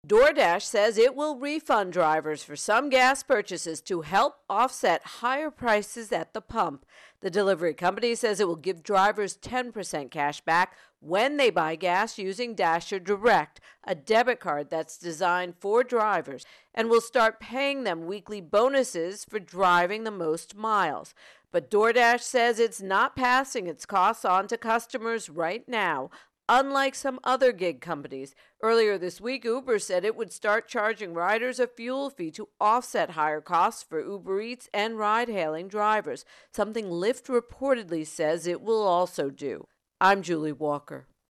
DoorDash Gas Prices intro and voicer